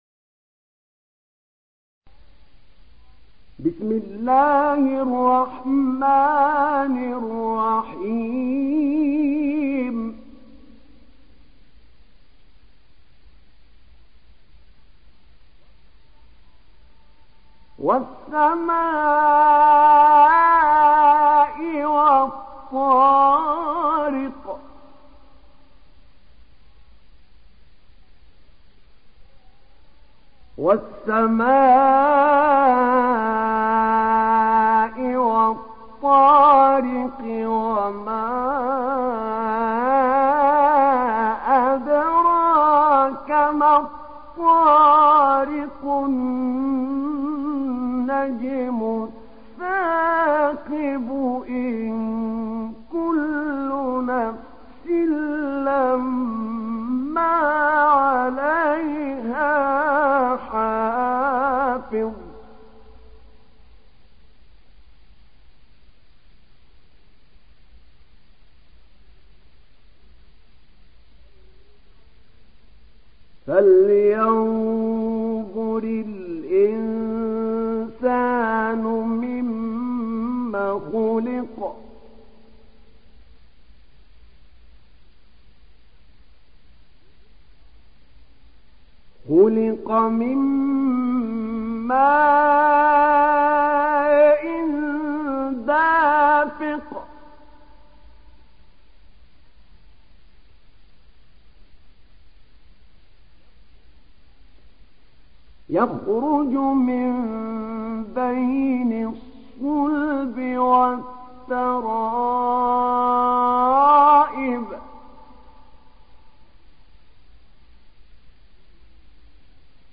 تحميل سورة الطارق mp3 بصوت أحمد نعينع برواية حفص عن عاصم, تحميل استماع القرآن الكريم على الجوال mp3 كاملا بروابط مباشرة وسريعة